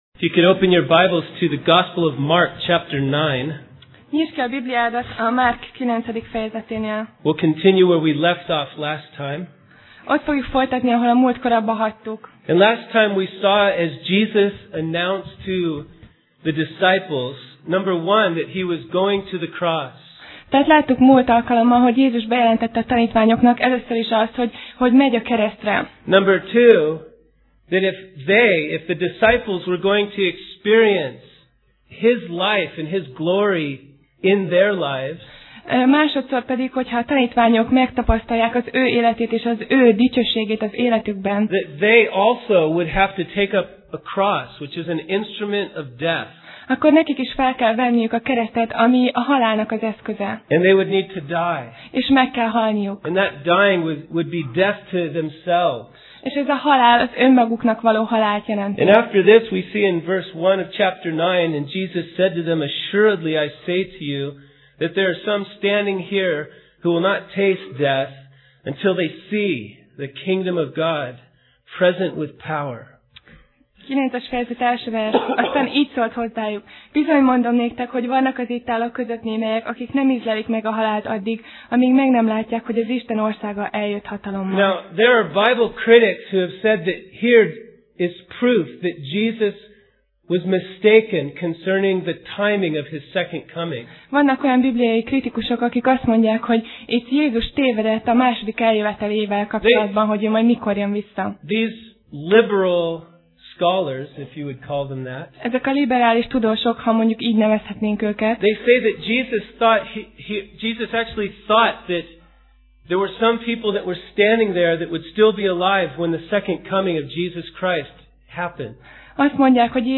Alkalom: Vasárnap Reggel